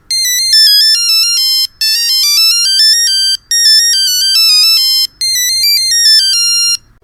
13. Волнительный старый рингтон